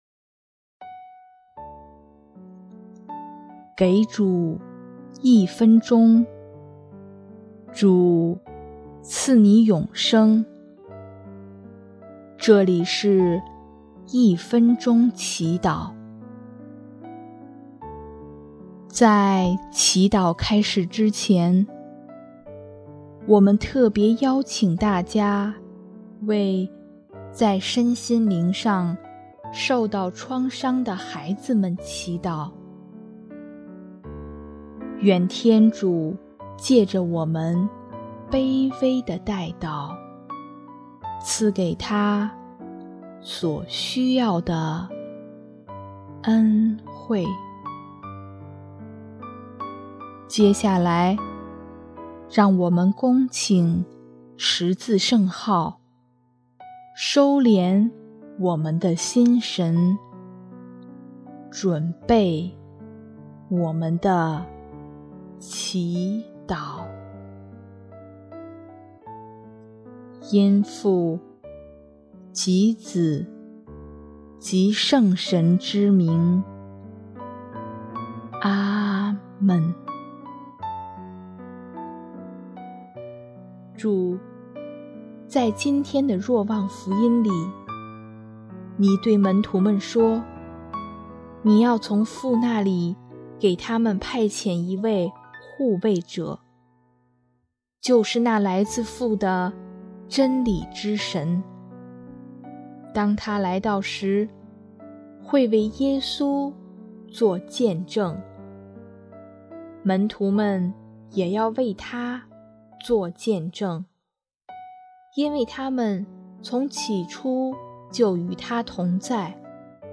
音乐 ：第二届华语圣歌大赛参赛歌曲《一生寻求你》（为在身心灵上受到创伤的孩子们祈祷）